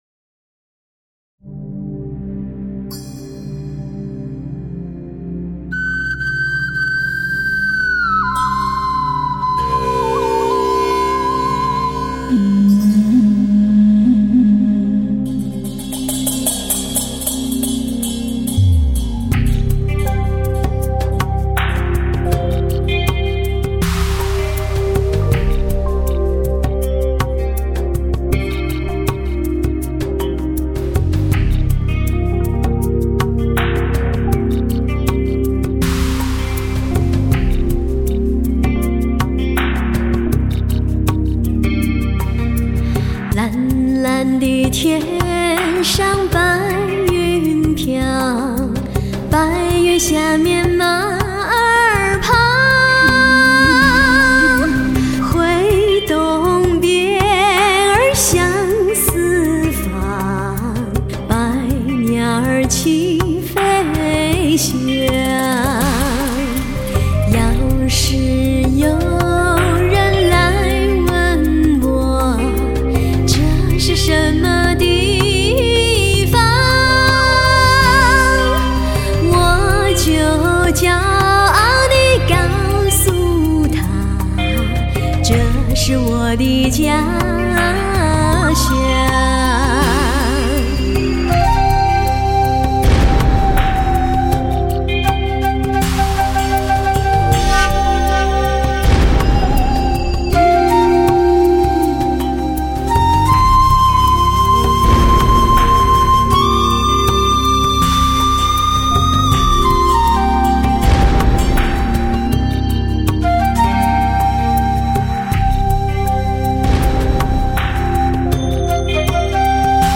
高级音响专用试音标准，欧美全车系兼容。
蒙古族独特的“呼麦”，发烧音质精心制作。